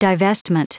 Prononciation du mot divestment en anglais (fichier audio)
Prononciation du mot : divestment